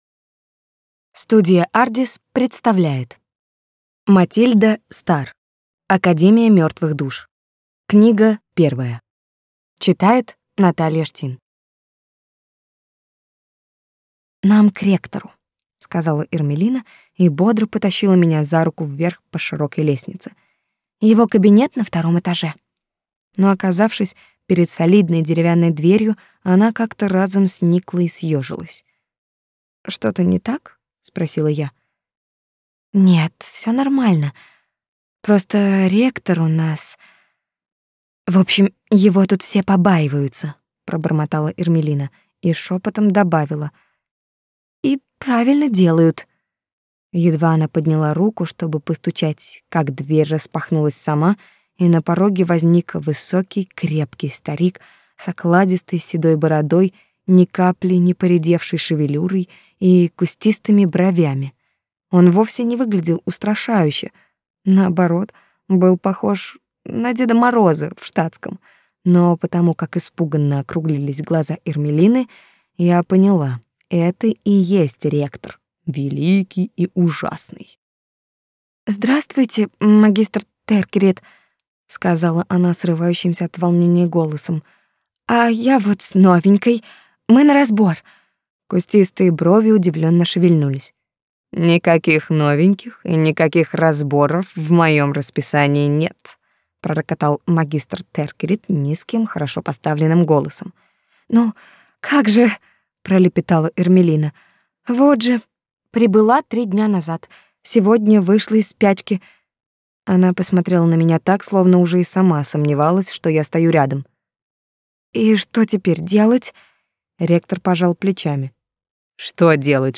Аудиокнига Академия мертвых душ - купить, скачать и слушать онлайн | КнигоПоиск